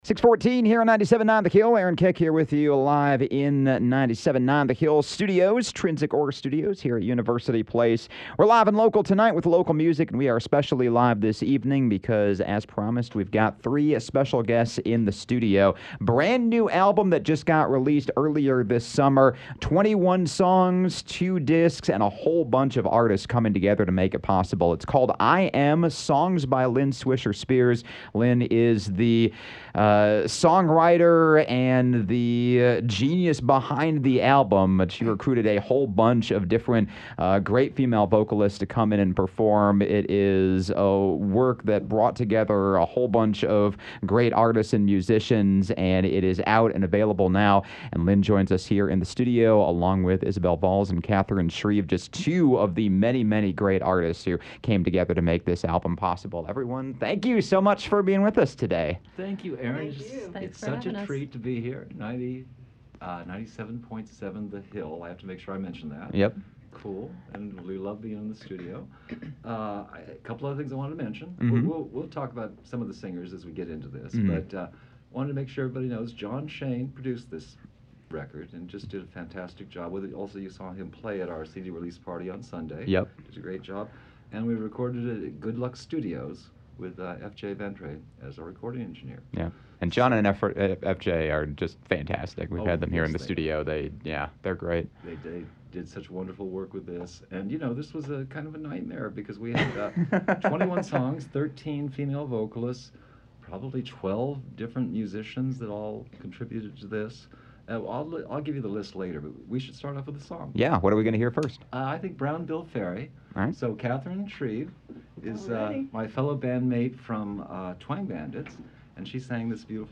accompanied by two guest singers.